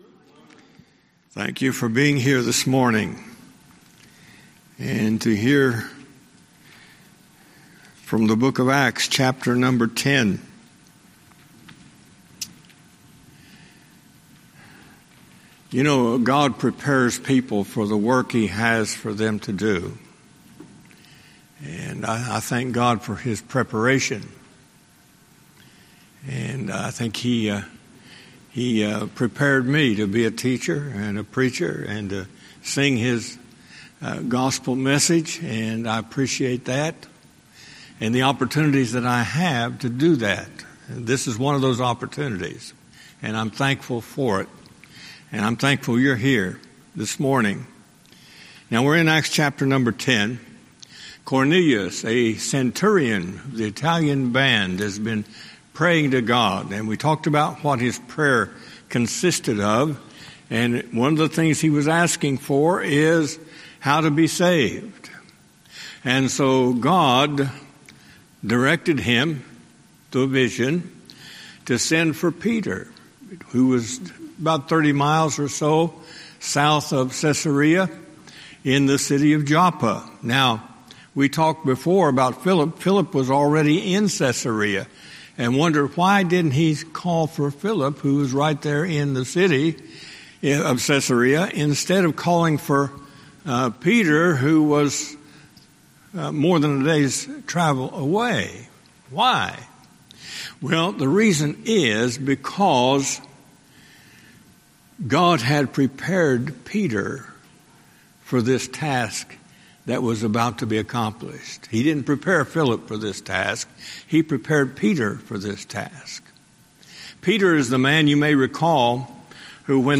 Sermons
Email Details Series: Guest Speaker Date